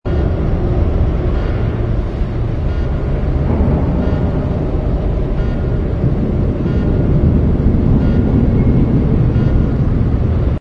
ambience_pirate.wav